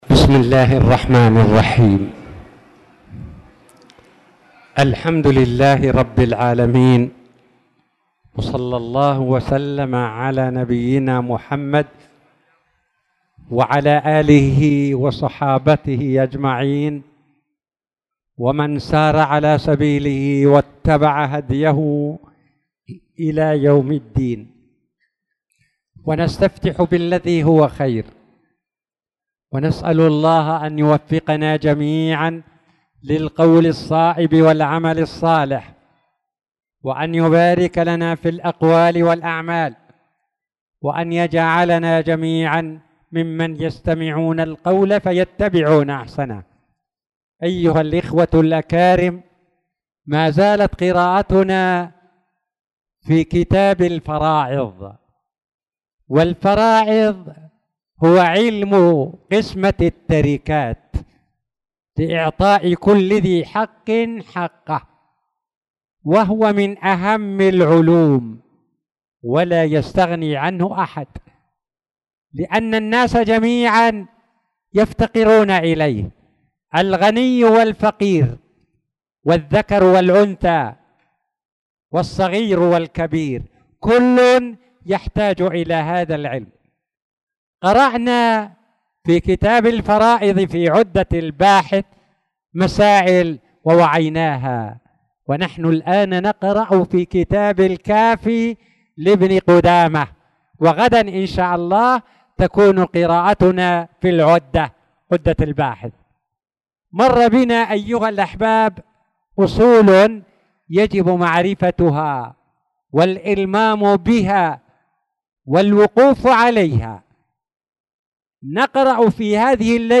تاريخ النشر ١٩ شوال ١٤٣٧ هـ المكان: المسجد الحرام الشيخ